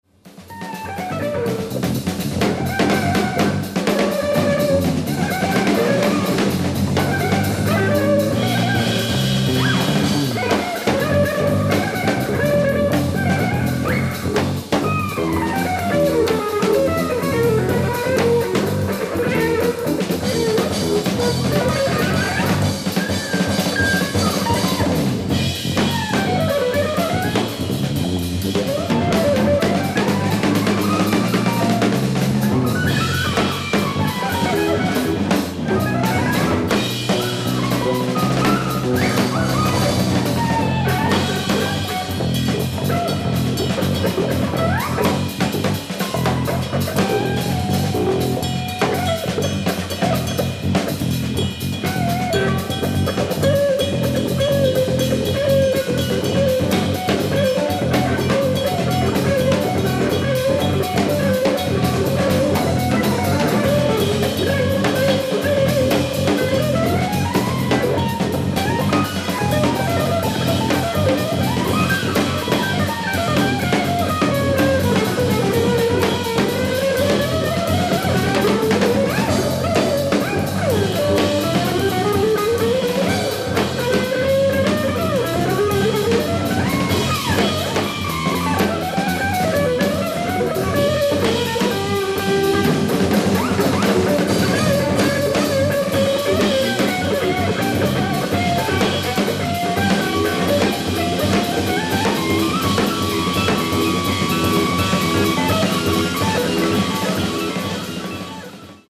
ライブ・アット・ブレーメン、ドイツ
※試聴用に実際より音質を落としています。